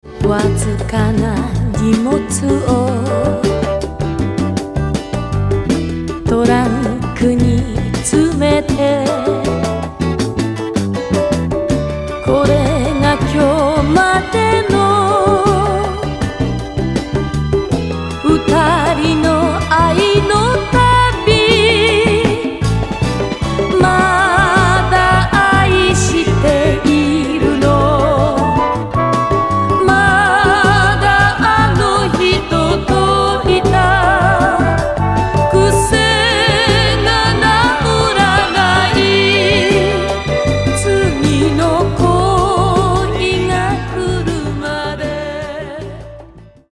ジャンル：和製ポップス